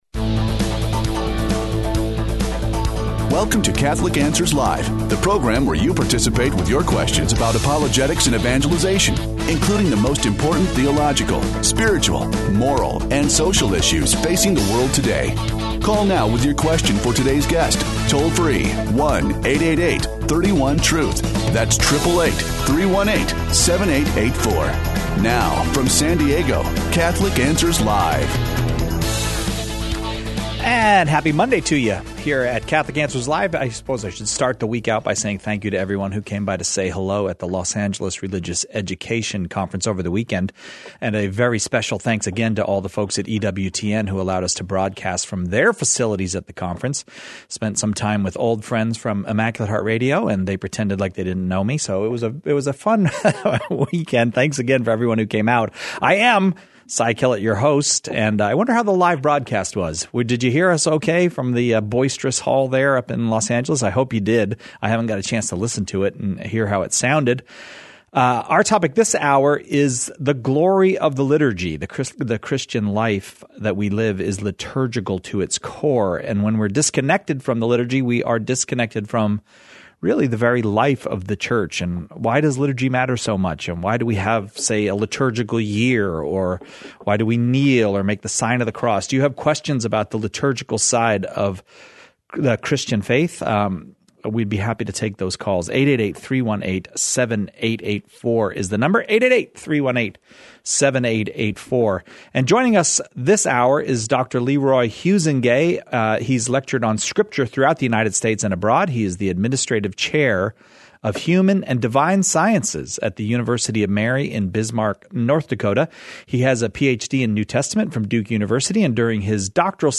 He takes questions about the current state of liturgy, and the meaning of certain liturgical practices.